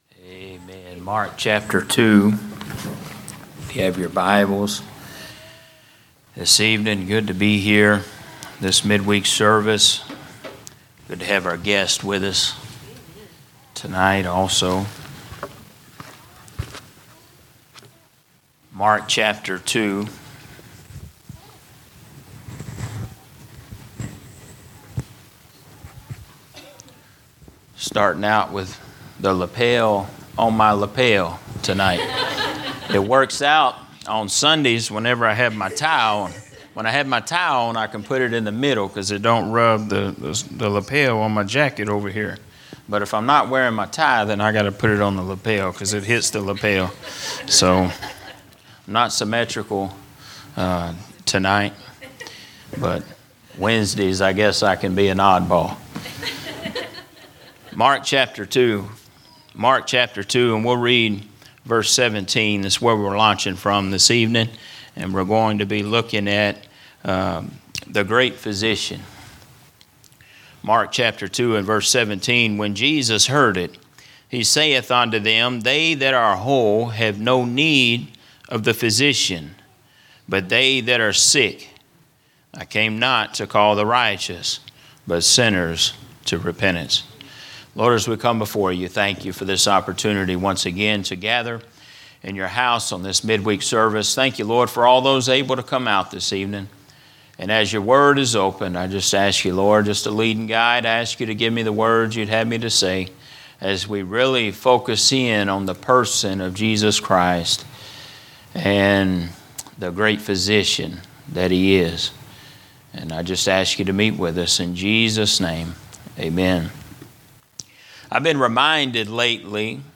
Sermons not part of a specific series